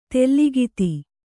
♪ tellagiti